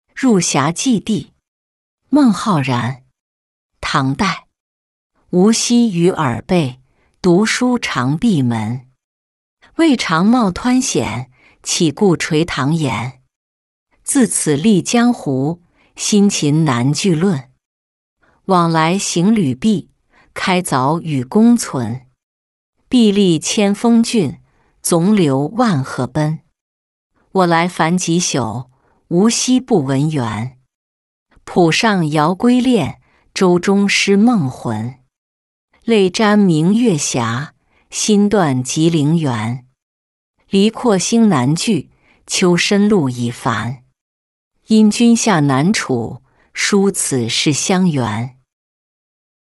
入峡寄弟-音频朗读